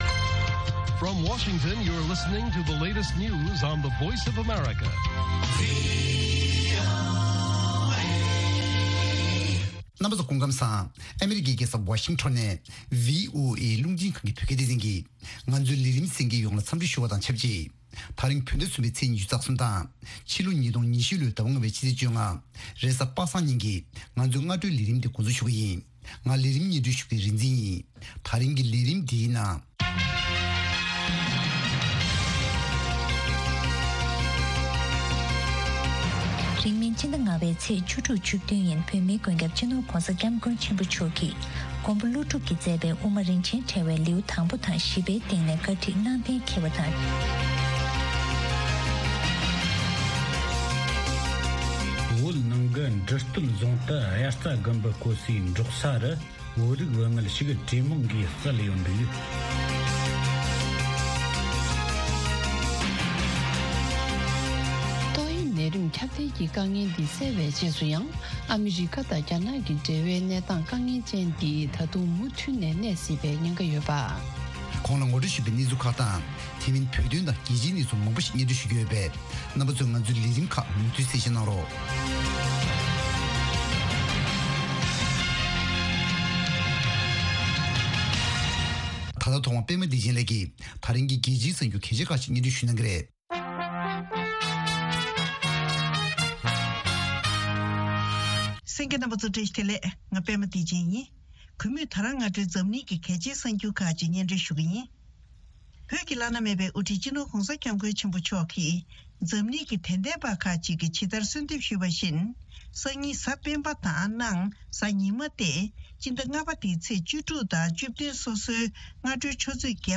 ཉིན་ལྟར་ཐོན་བཞིན་པའི་བོད་མའི་གནད་དོན་གསར་འགྱུར་ཁག་རྒྱང་སྲིང་ཞུས་པ་ཕུད། དེ་མིན་དམིགས་བསལ་ལེ་ཚན་ཁག་ཅིག་རྒྱང་སྲིང་ཞུ་བཞིན་ཡོད།